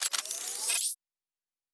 pgs/Assets/Audio/Sci-Fi Sounds/Weapons/Additional Weapon Sounds 5_2.wav at master
Additional Weapon Sounds 5_2.wav